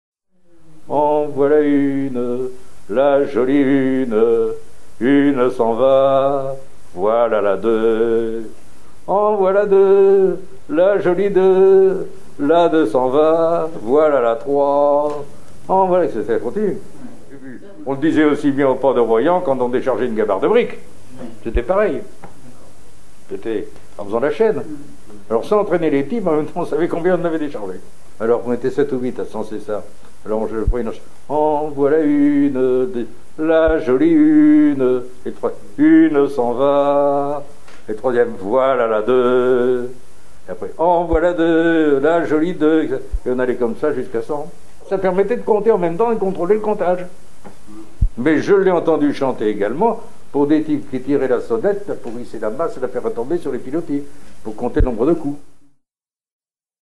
Saint-Palais-sur-Mer
Le voilà le joli deux, etc. Voir la liste des oeuvres Pays France Usage d'après l'analyste circonstance : maritimes ; Usage d'après l'informateur circonstance : maritimes ; Incipit du couplet En voilà un.
Genre énumérative
Pièce musicale éditée